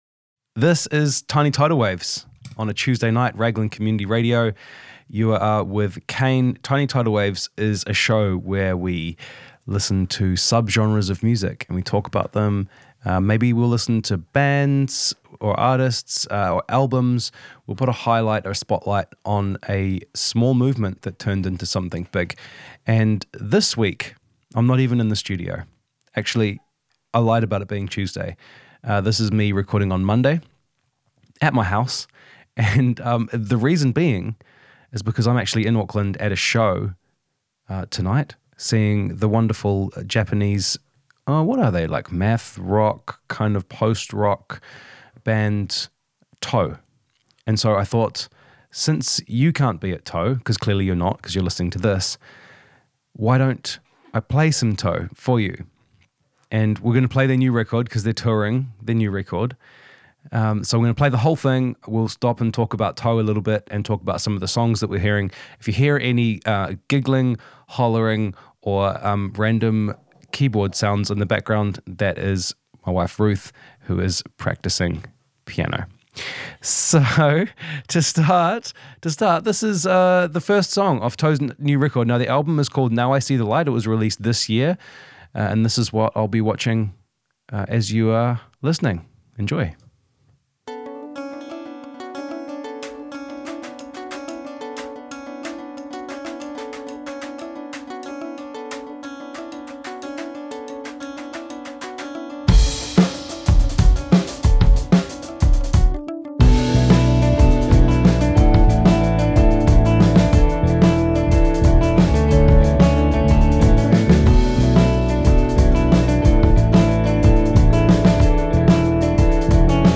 Japanese post/math rock